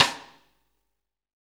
SNR JAZZ 06R.wav